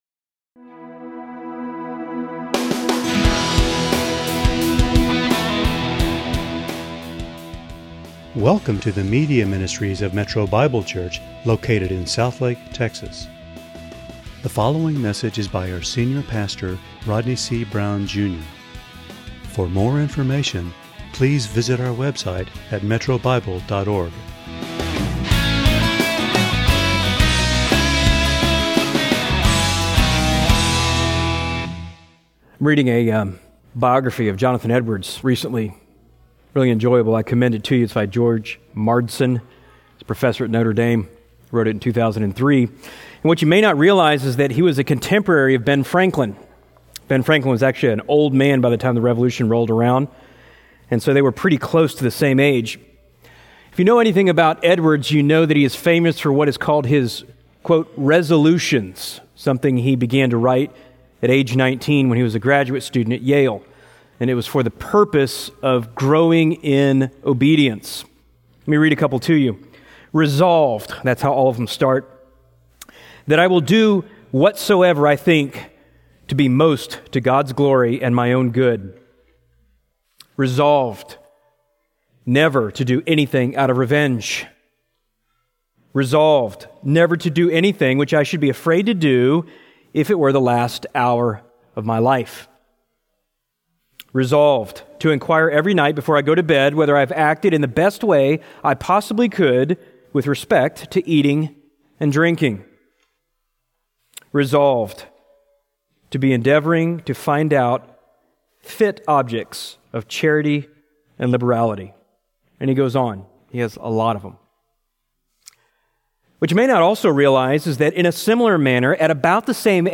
× Home About sermons Give Menu All Messages All Sermons By Book By Type By Series By Year By Book Bootstrap Religion The law did not cause death, but neither will it produce life.